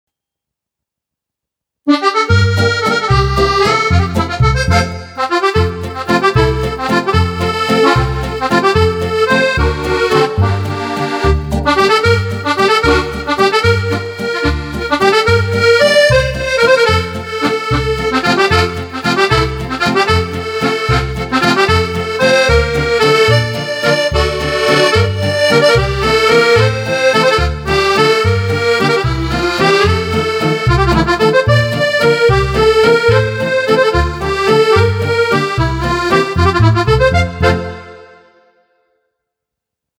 Patch Demos
2. Akk-Musette
Akk-Musette.mp3